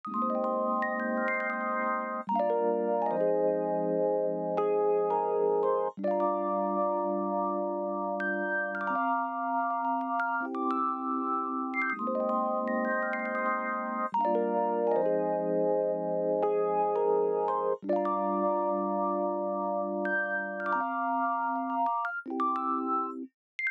06 rhodes A.wav